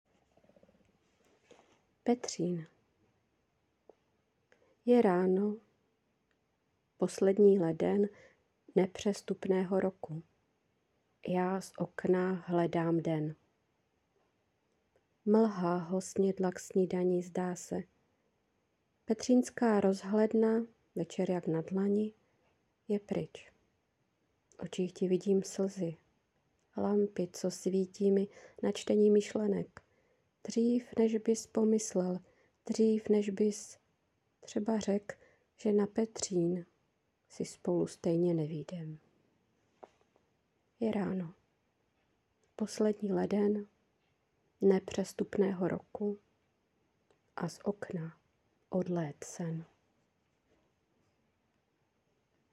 Ta recitace tvé básni sluší.